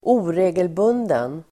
Uttal: [²'o:re:gelbun:den]